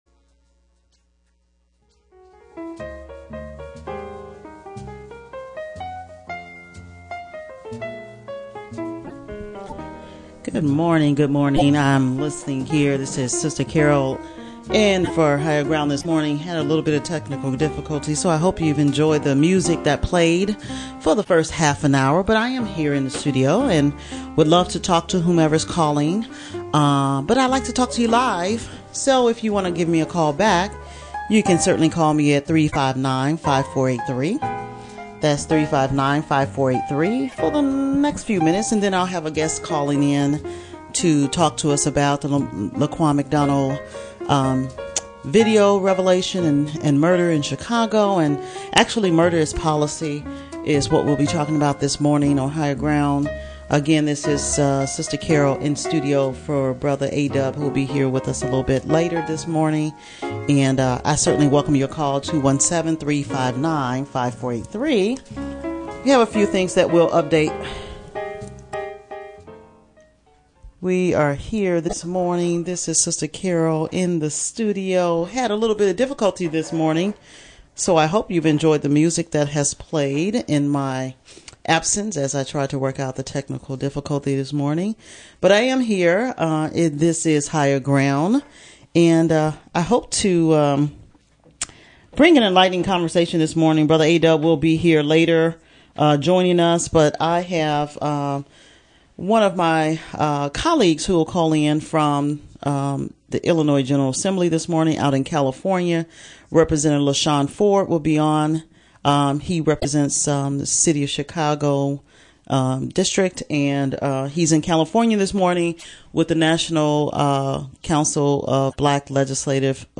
Higher Ground focuses on local affairs and on personal improvement that leads to community development. This public affairs show discusses issues that primarily affect the African-American community, however, the larger social fabric of America is also strategically interwoven into the dialogue.